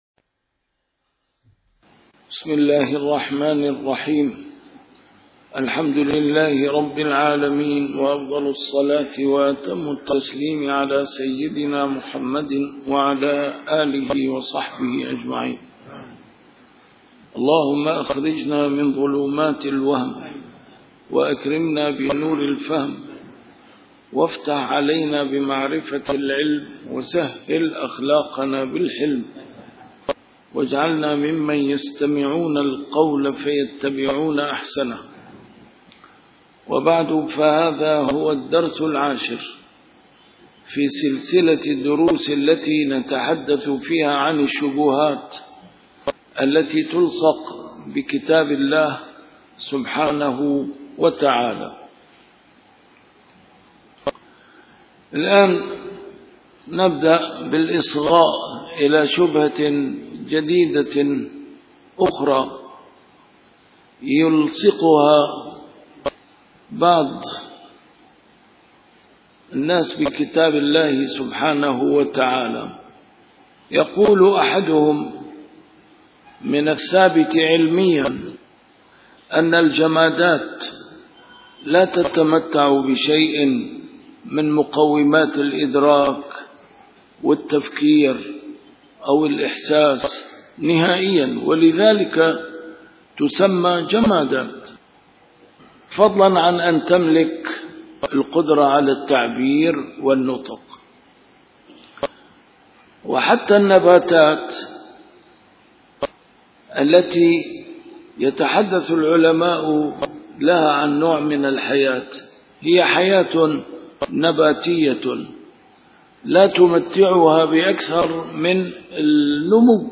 لايأتيه الباطل / الدرس العاشر - دعوى معارضة القرآن للعلم